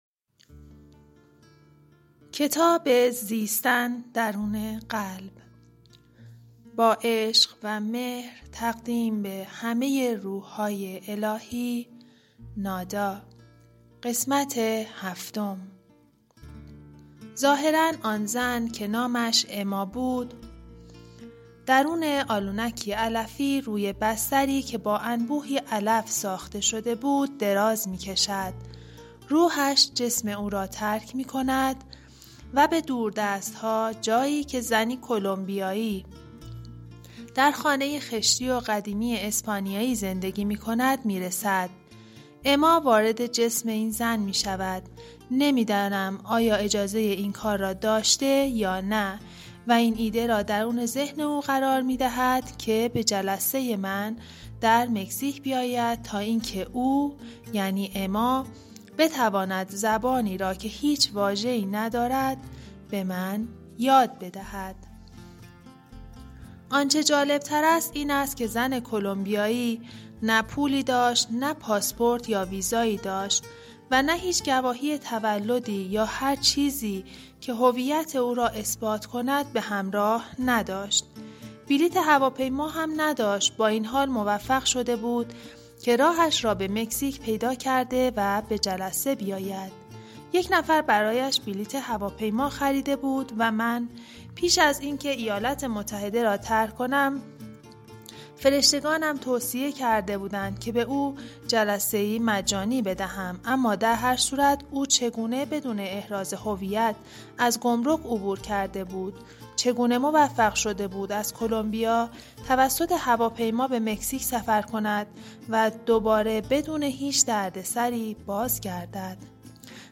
کتاب گویای زیستن درون قلب نویسنده درونوالو ملچیزدک / قسمت7